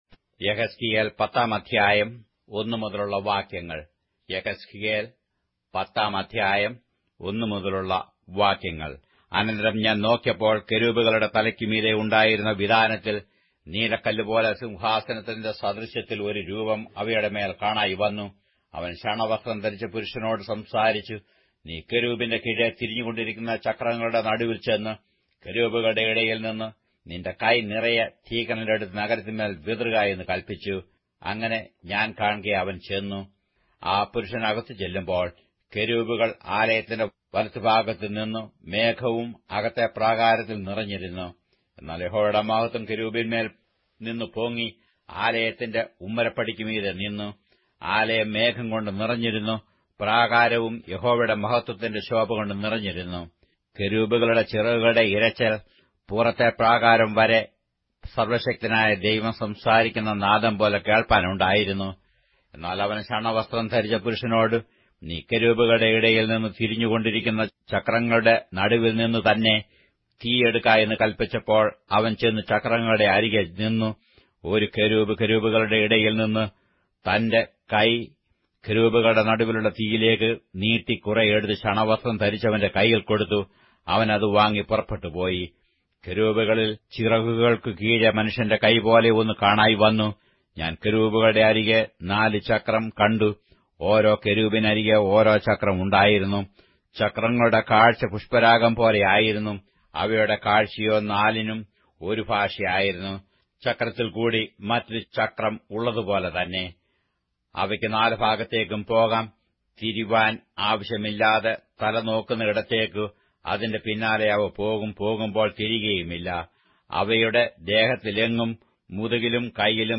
Malayalam Audio Bible - Ezekiel 22 in Mrv bible version